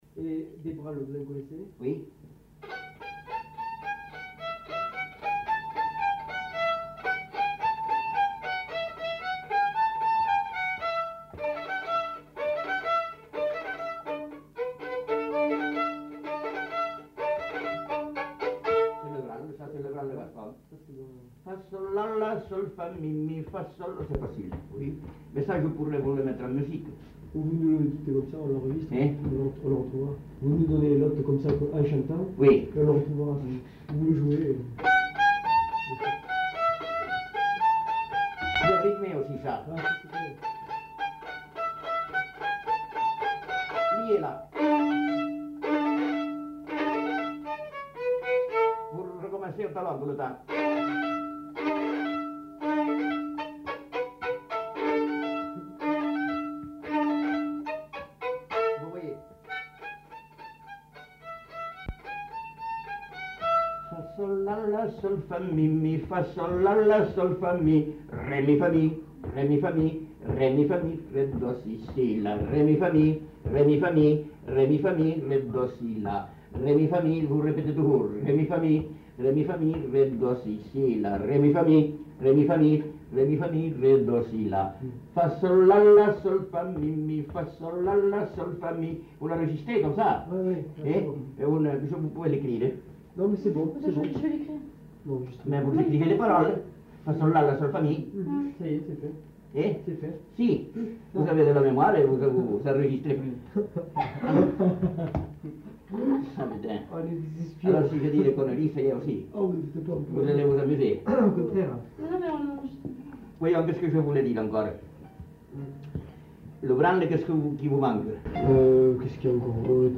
Aire culturelle : Astarac
Lieu : Orbessan
Genre : morceau instrumental
Instrument de musique : violon
Danse : borregada
Notes consultables : Explique comment jouer l'air puis chante les notes.